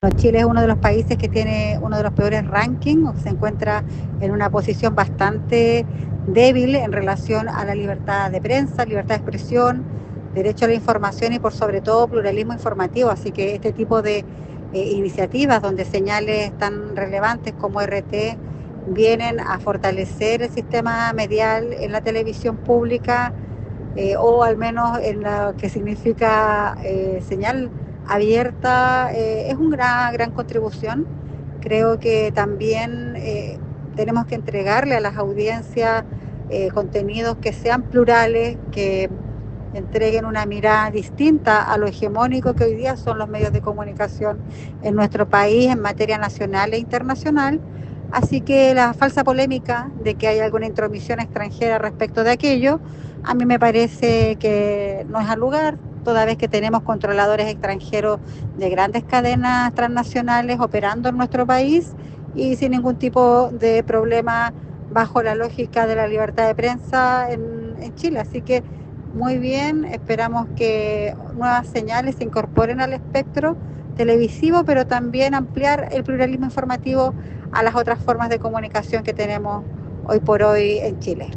Más el audio de la diputada Nathalie Castillo que está en archivo adjunto
audio_diputada_nathalie_castillo.m4a